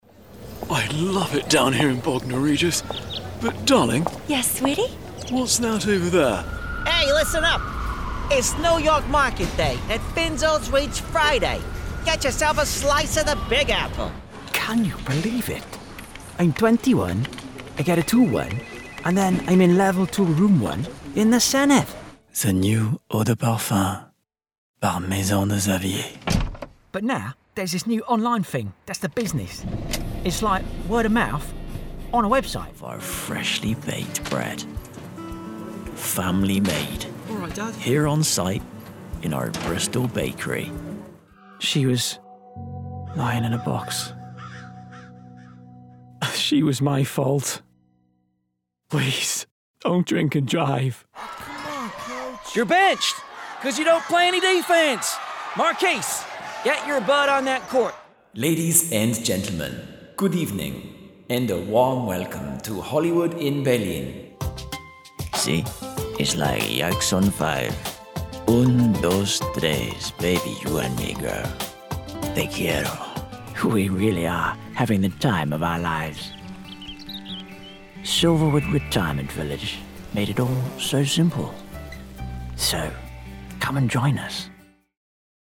Inglés (Británico)
Joven, Natural, Travieso, Accesible, Amable